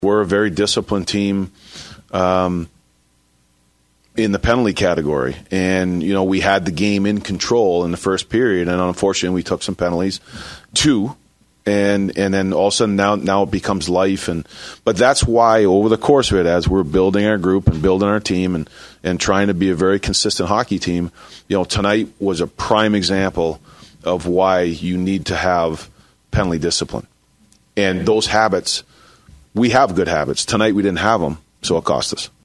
Wild head coach John Hynes on his team taking too many penalties and it haunted them.